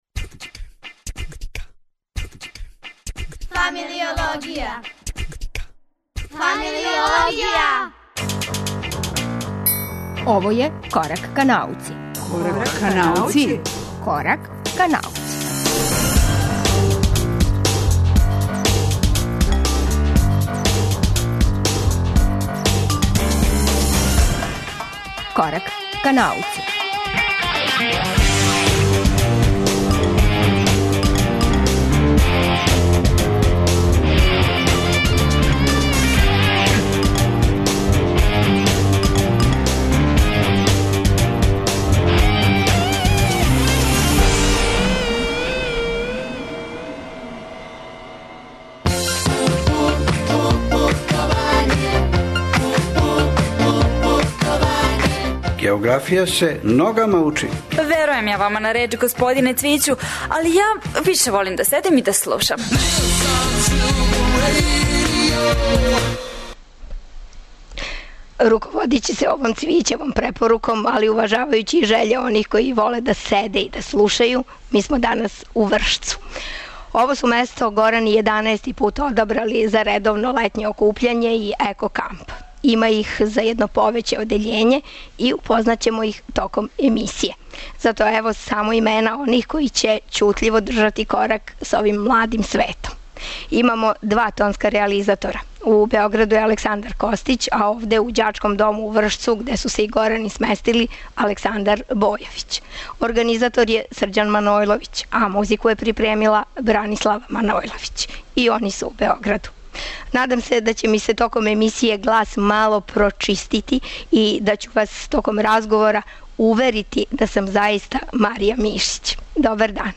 Корак ка науци емитујемо из Горанског кампа у Вршцу.